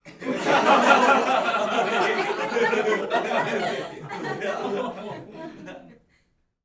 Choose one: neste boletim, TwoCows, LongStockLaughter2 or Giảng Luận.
LongStockLaughter2